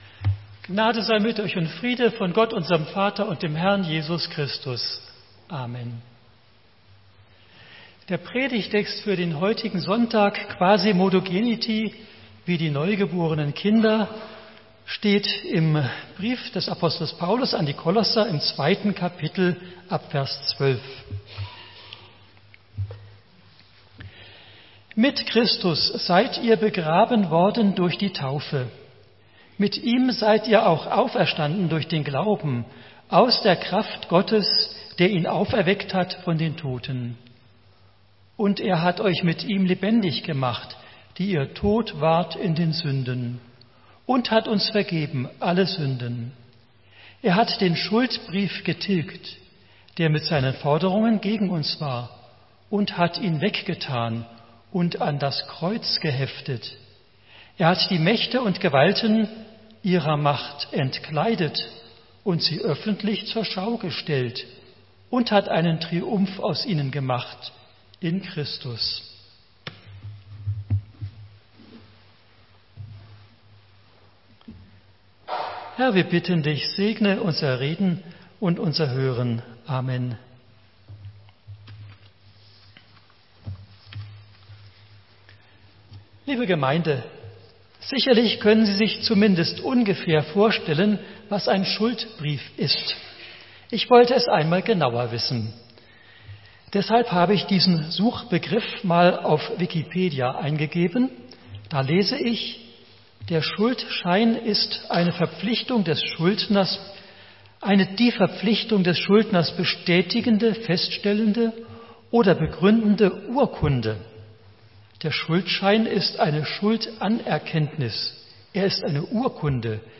Predigten - EKI - Öschelbronn